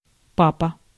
Ääntäminen
Synonyymit apostoille souverain pontife patriarche Ääntäminen France: IPA: [pap] Haettu sana löytyi näillä lähdekielillä: ranska Käännös Ääninäyte Substantiivit 1.